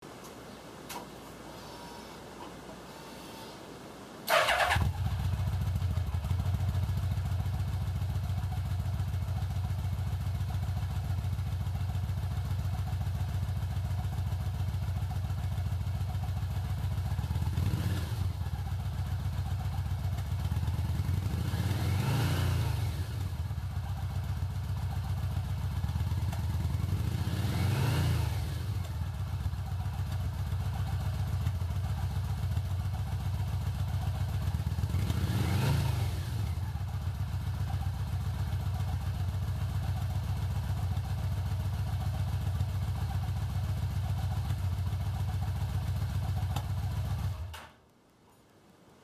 折角なので約1m後方よりデジカメの動画モードで録音。
純正マフラー音 (mp3 128kbit/sec) サイズ 770k